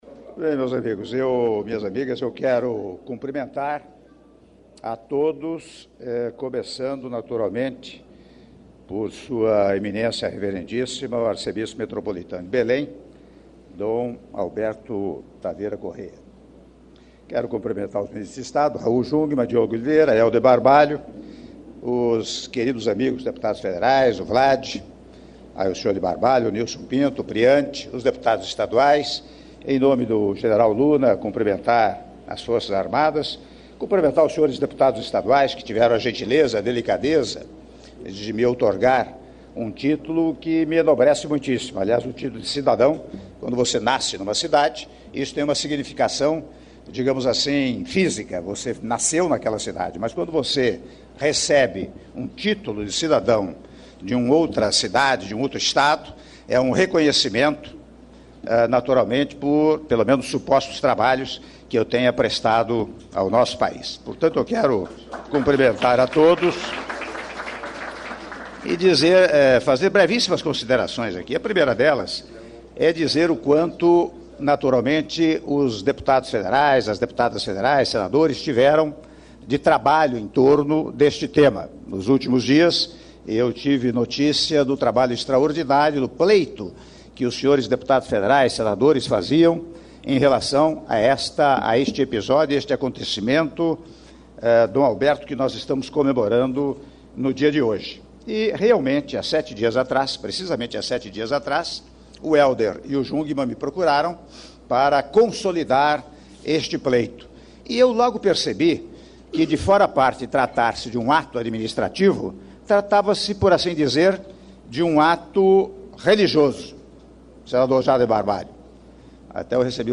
Áudio do discurso do Presidente da República, Michel Temer, na cerimônia de assinatura de protocolo de intenções sobre destinação social de área a ser utilizada pela Basílica de Nossa Senhora de Nazaré -Belém/PA- (08min01s)